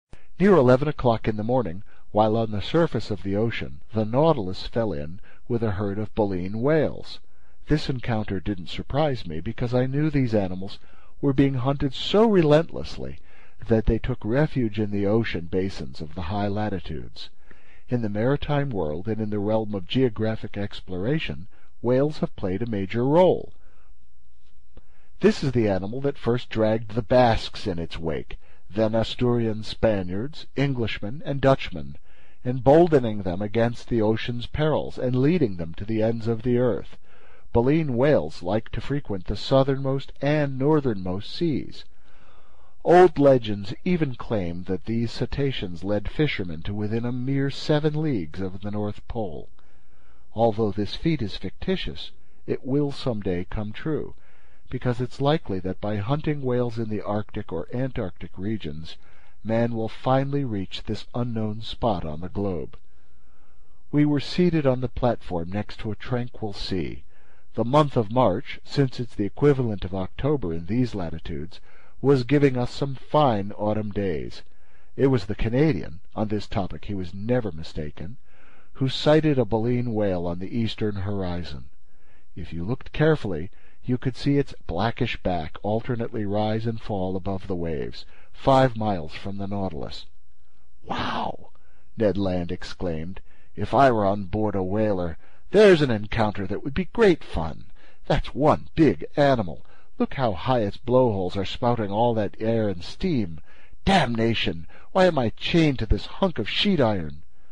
英语听书《海底两万里》第423期 第26章 大头鲸和长须鲸(4) 听力文件下载—在线英语听力室
在线英语听力室英语听书《海底两万里》第423期 第26章 大头鲸和长须鲸(4)的听力文件下载,《海底两万里》中英双语有声读物附MP3下载